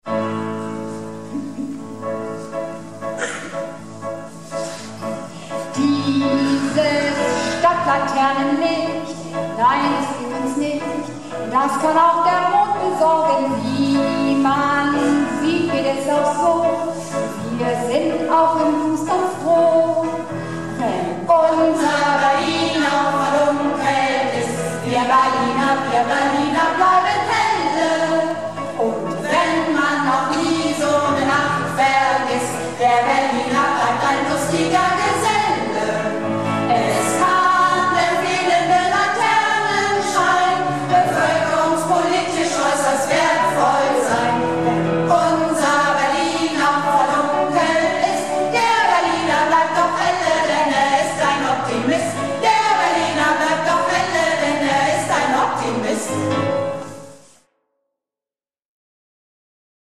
Theater - "Du musst es wagen - Sünner Tegenstöten word nümms deftig" am 21.04.12 in Loquard